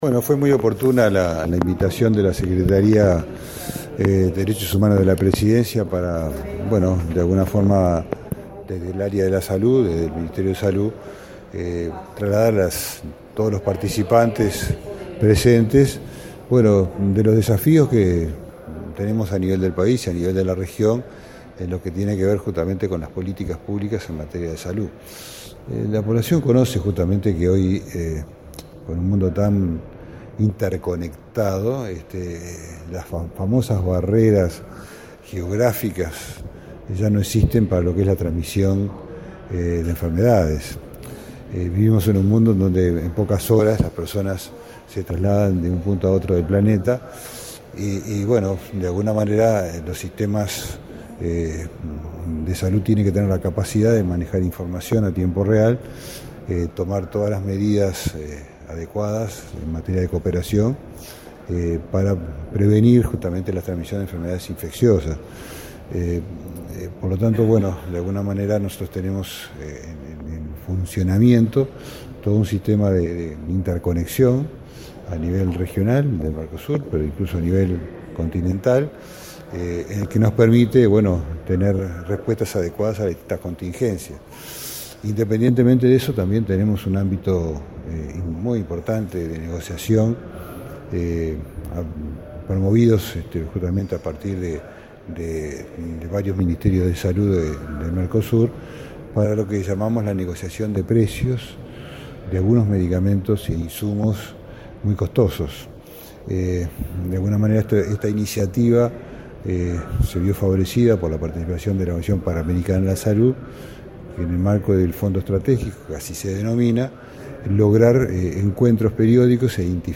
En ese marco, el ministro de Salud Pública, Jorge Basso, manifestó que existe un sistema de interconexión que permite tener respuestas adecuadas ante distintas contingencias sanitarias. Destacó que el Mercosur negocia la compra de medicamentos de alto precio y otros insumos.